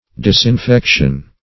\dis`in*fec"tion\
disinfection.mp3